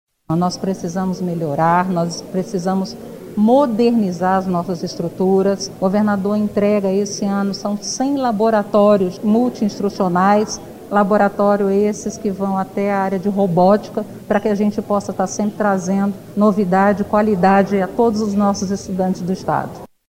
Sonora-Kuka-Chaves-secretaria-de-Educacao-e-Desporto-do-Amazonas.mp3